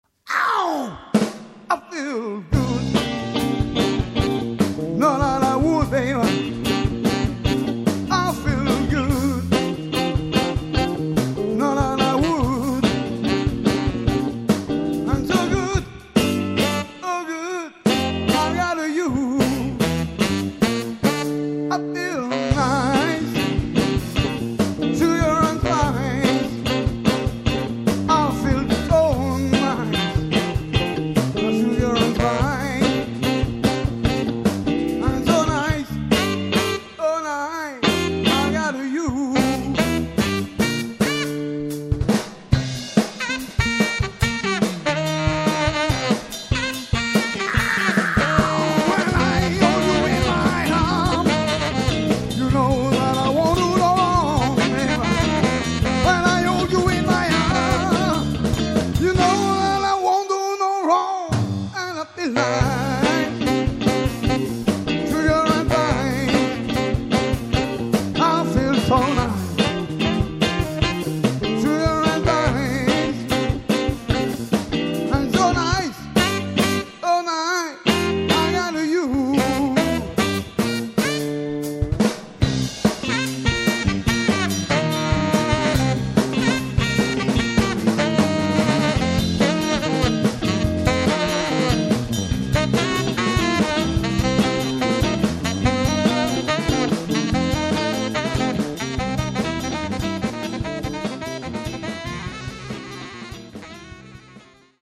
Extrait reprise